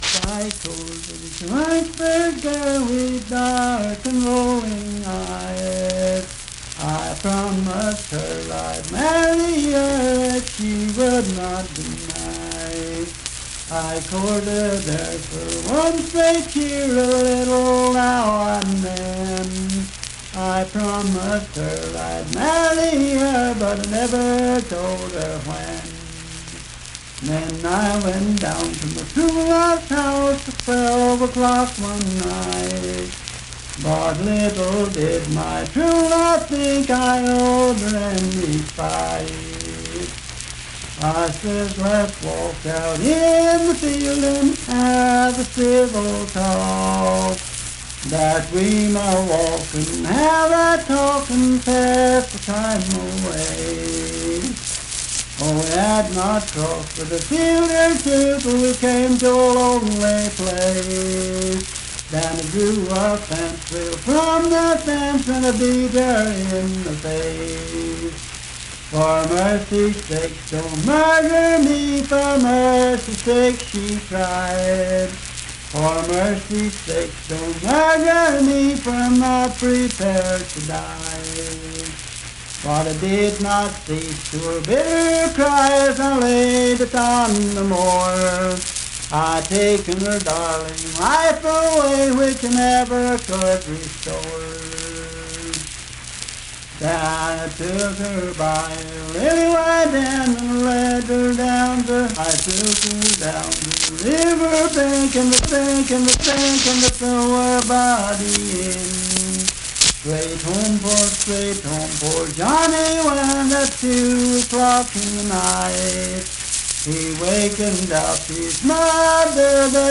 Unaccompanied vocal music
Verse-refrain 11(4).
Performed in Dryfork, Randolph County, WV.
Voice (sung)